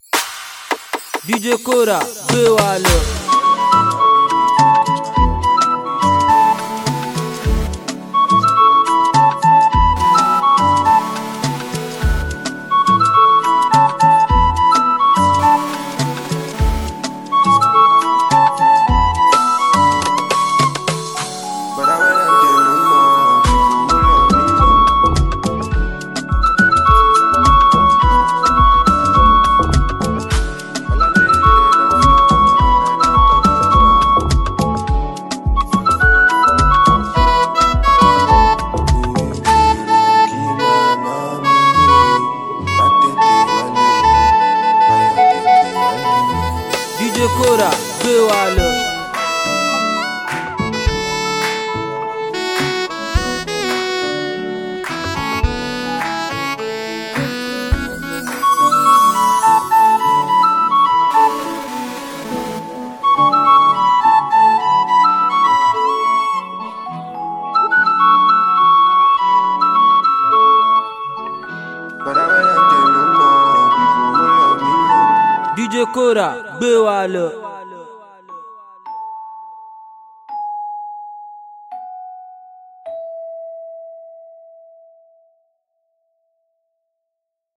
gbedu song